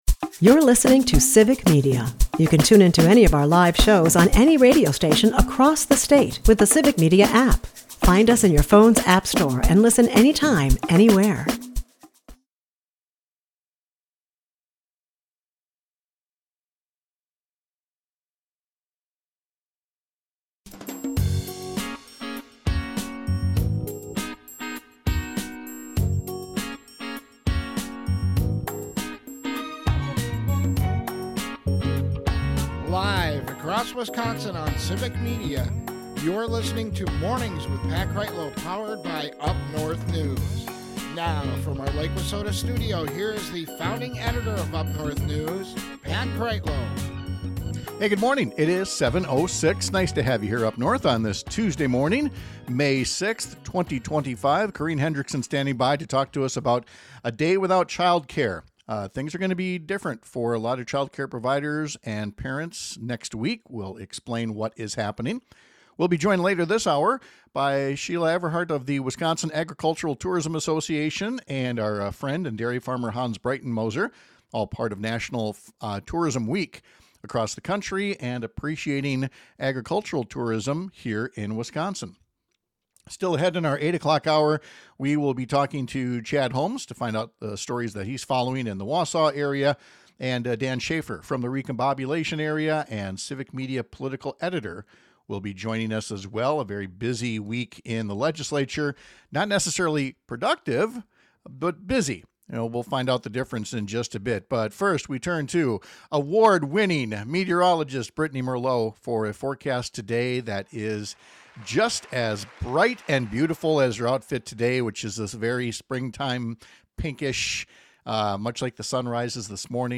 giving you the highlights from our show including a discussion about child care